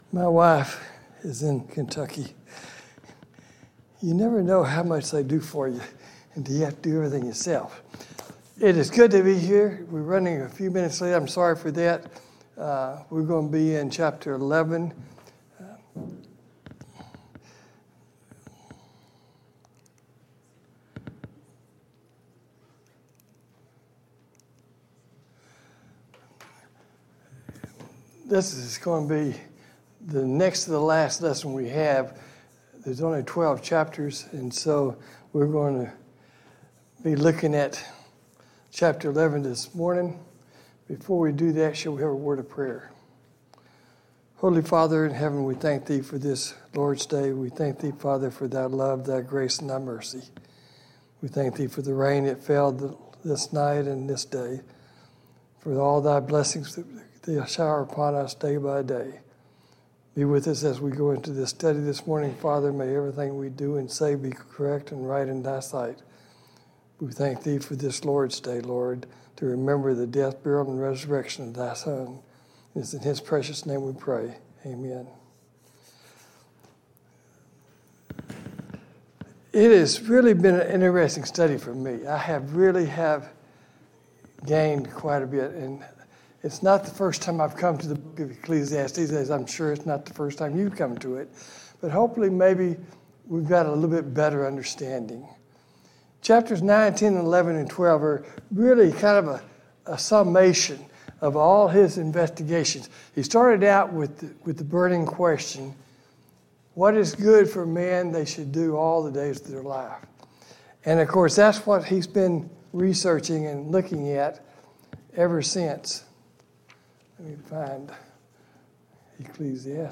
Sunday Morning Bible Class Topics